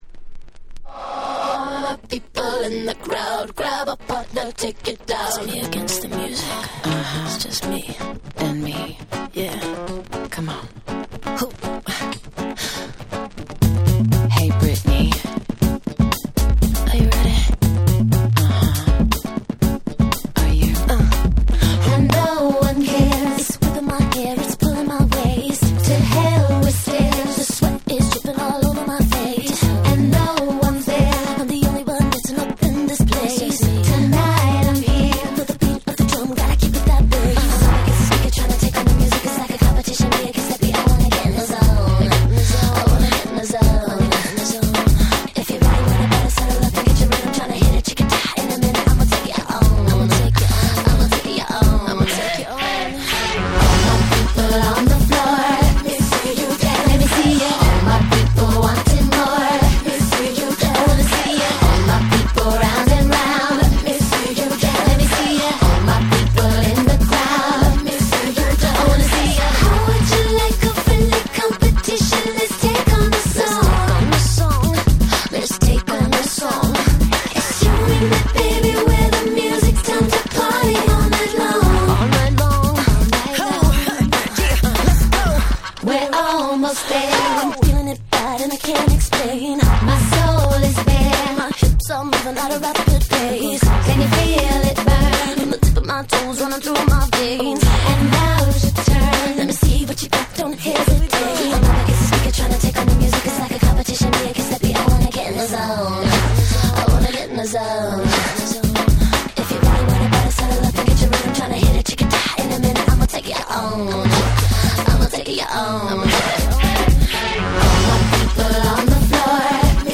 03' Big Hit R&B / Pops !!